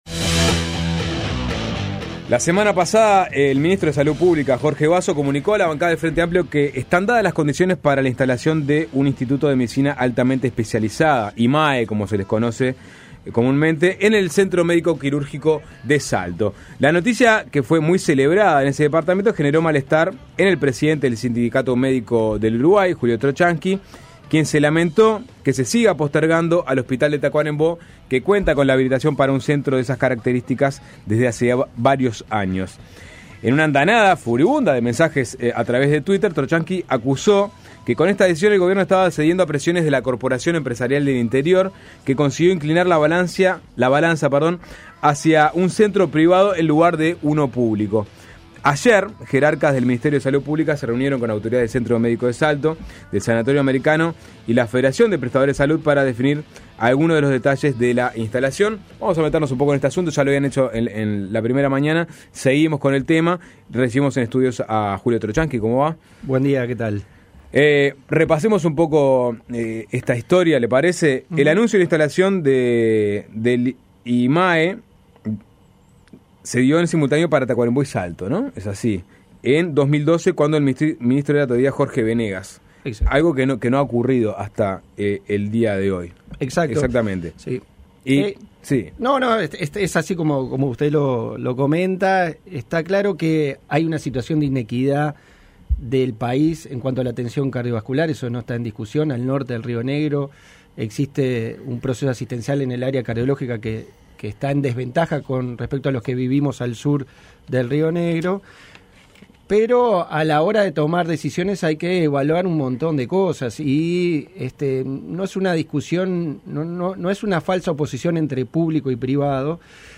entrevista en suena tremendo